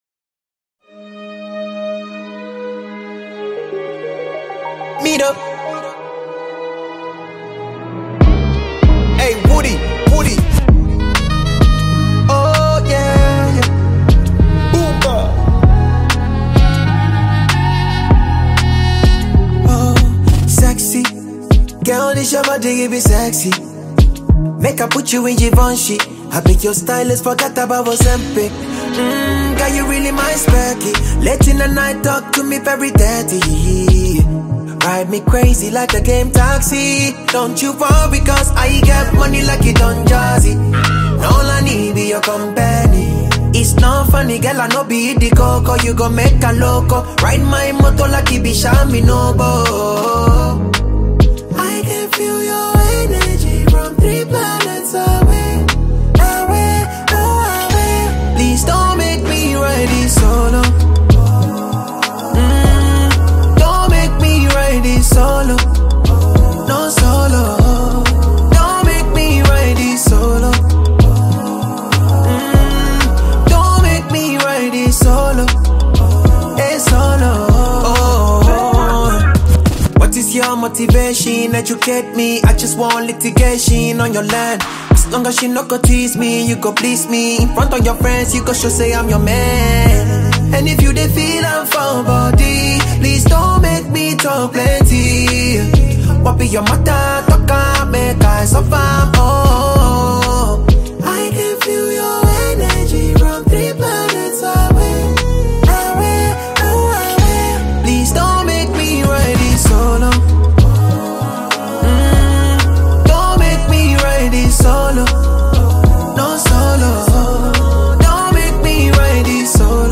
smooth and emotionally driven Afrobeat record
• Genre: Afrobeat / Afropop